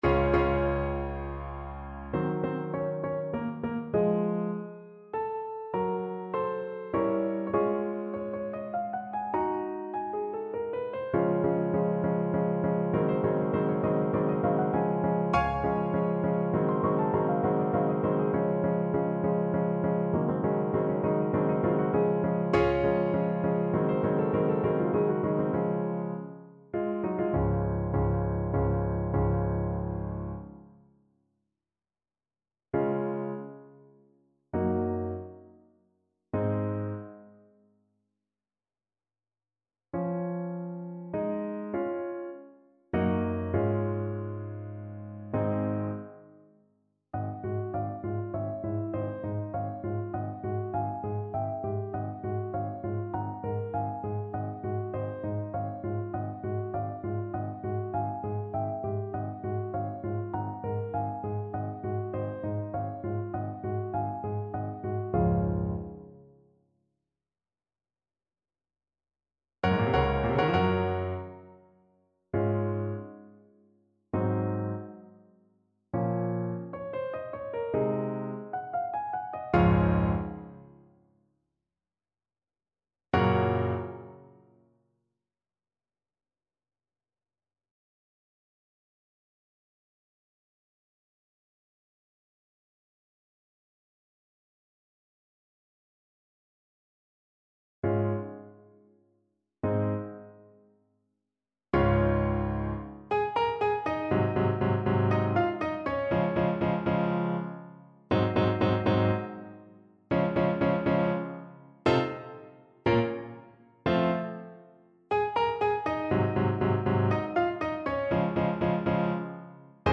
Alto Saxophone version
6/8 (View more 6/8 Music)
Andante =c.100
Classical (View more Classical Saxophone Music)